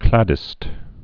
(klădĭst, klādĭst)